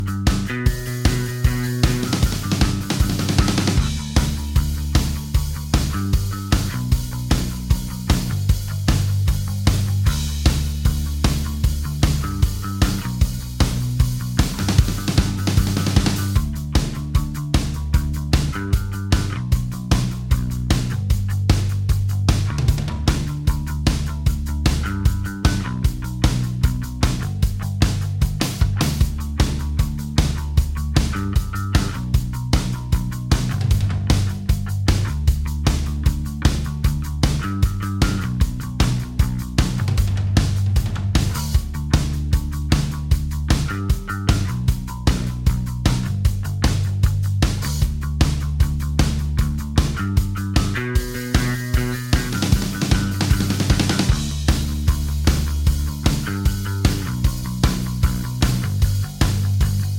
Minus Main Guitar For Guitarists 3:03 Buy £1.50